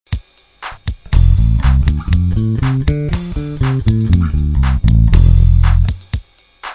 Les gammes à la basse: quel travail indispensable!!!
- Pentatonique majeure
pentatonique_majeure.wav